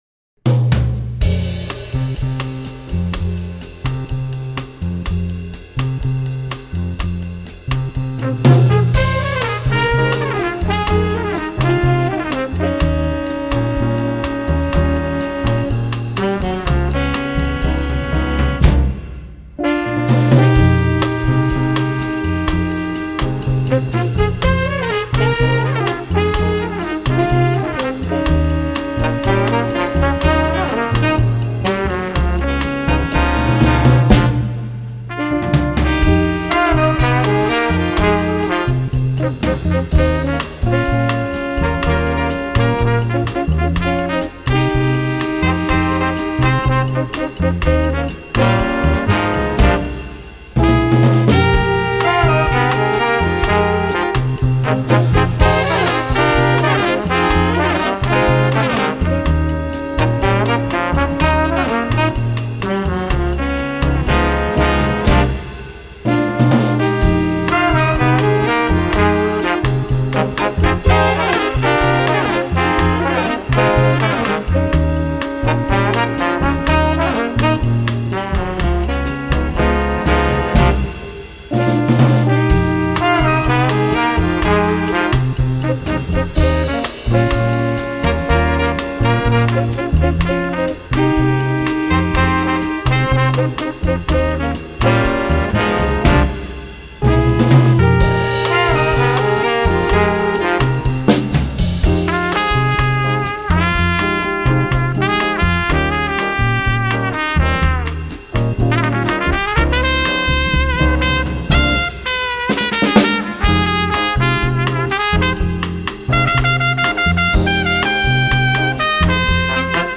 Voicing: Jazz Combo